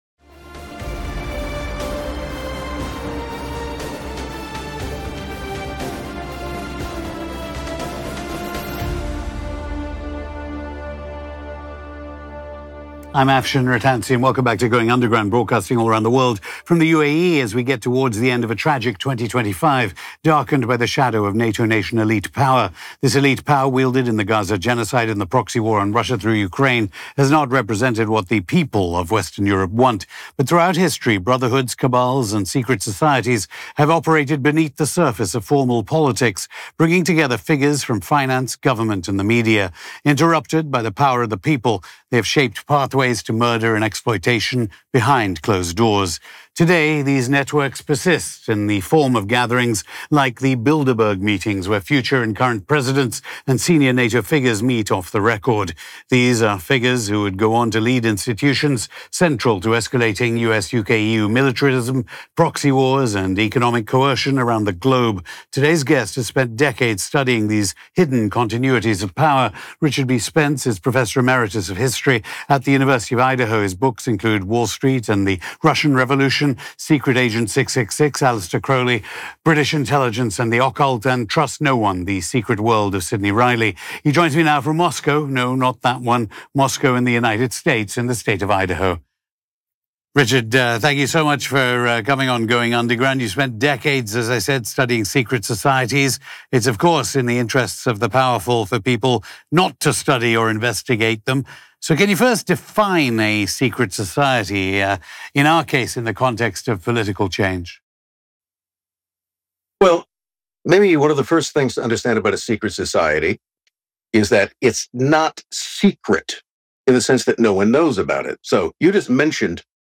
Hosted by Afshin Rattansi